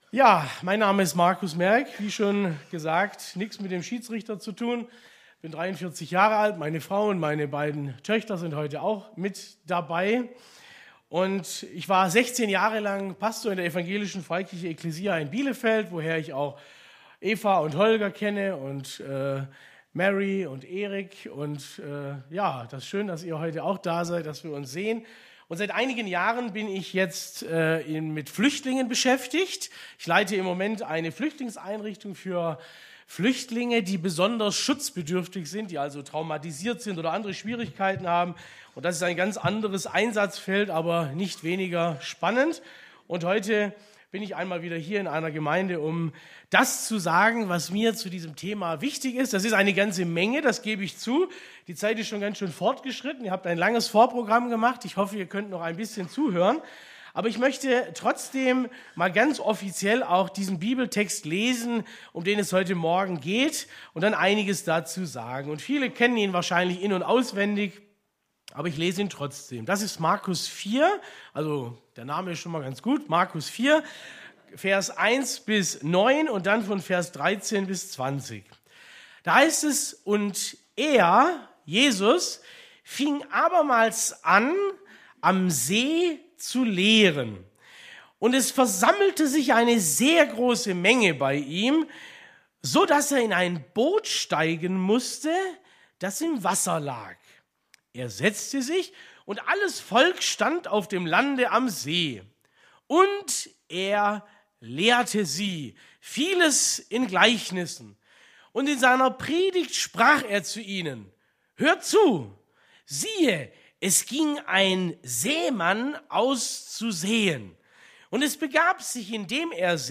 Predigt vom 10.